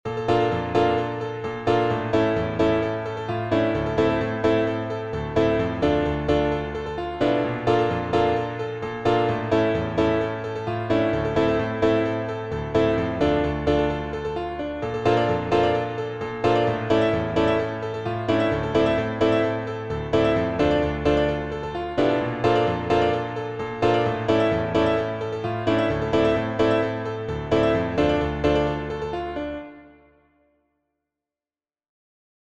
只是 30 秒的超短片段。
这个是直接导出的软件合成音频：